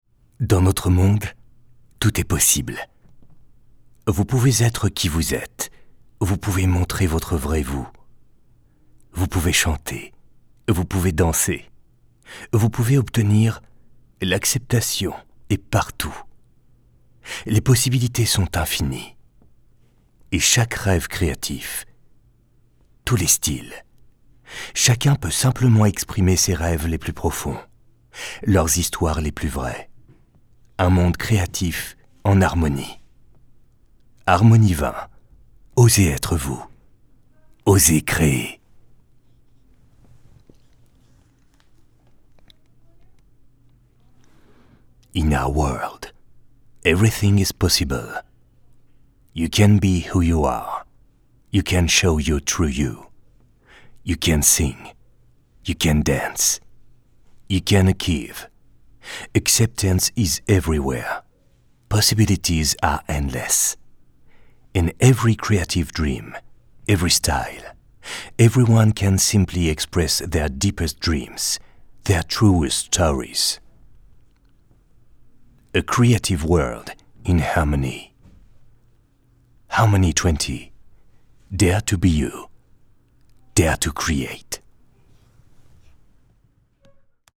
Male
30s, 40s
Confident, Corporate, Deep, Friendly, Natural, Warm
Voice reels
Microphone: Neumann U87